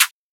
archived music/fl studio/drumkits/slayerx drumkit/snares